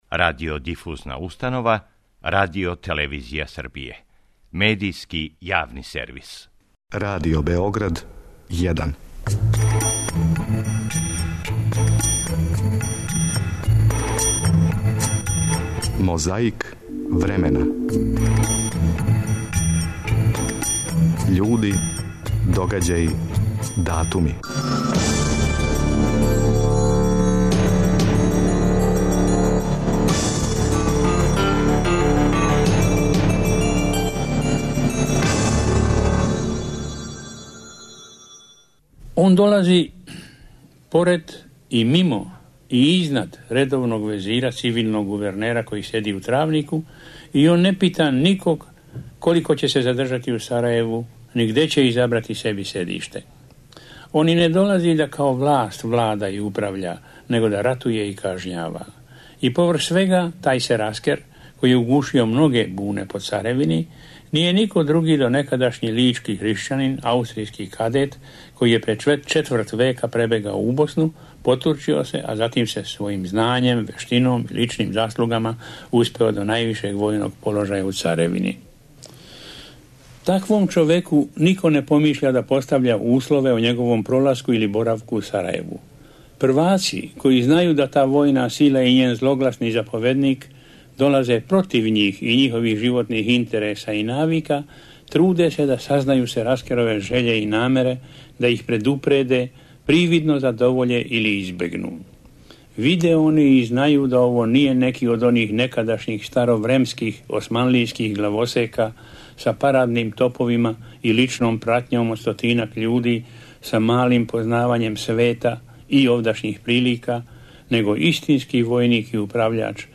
Ове суботе нашу борбу против пилећег памћења отвара Иво Андрић зато што је 10. децембра 1961. године добио Нобелову награду за књижевност. Чућемо како је писац реаговао на вест да је добио Нобелову награду.